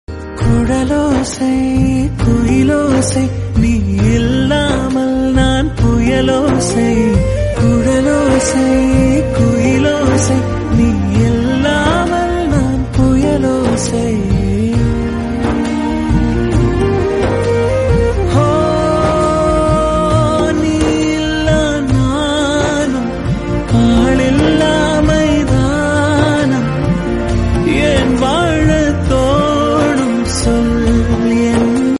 best flute ringtone download | love song ringtone
romantic ringtone